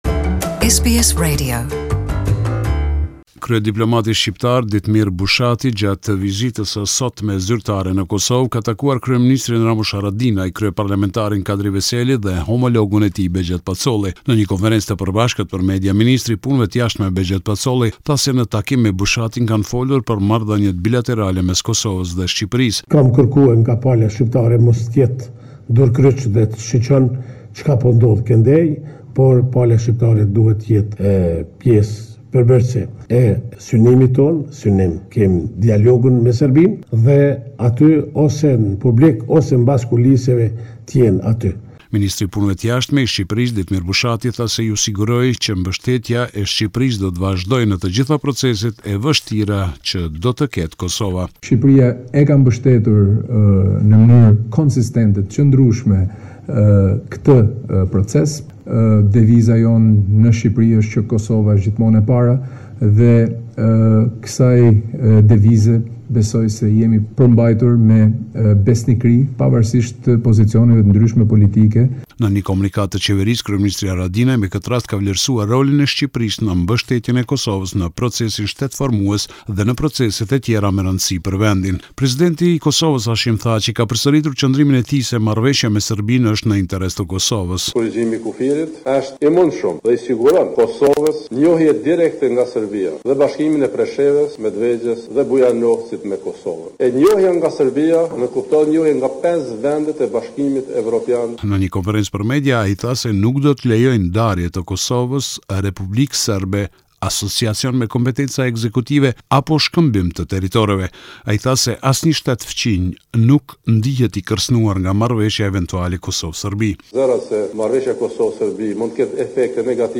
This is a report summarising the latest developments in news and current affairs in Kosova.